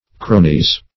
Cronies - definition of Cronies - synonyms, pronunciation, spelling from Free Dictionary
(kr[=o]"n[i^]z).